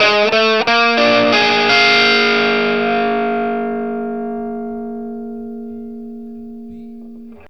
PICK1 BF7 60.wav